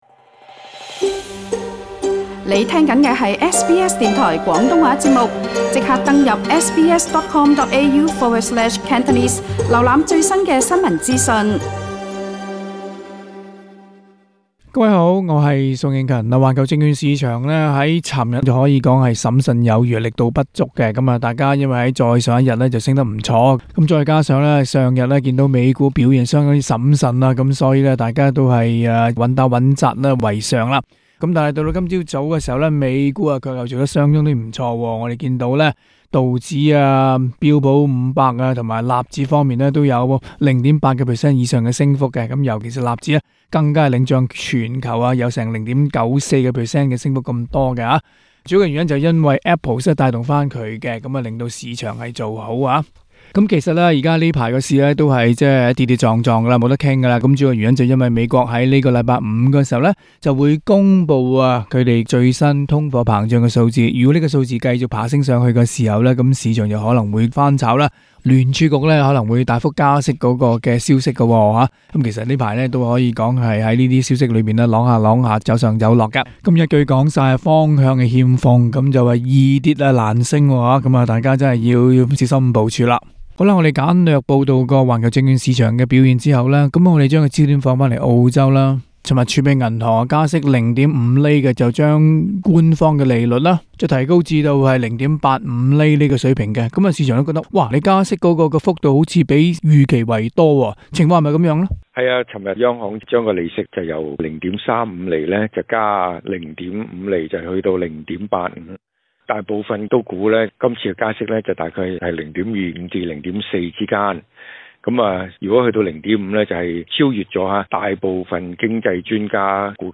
（詳情請收聽今日的訪談内容） 上證綜指周二在亞太區得天獨厚，取得0.17%的進賬，收於兩個月的高位 3,241.76點，原因與中國放寬新冠疫情限制，提振了投資者的信心。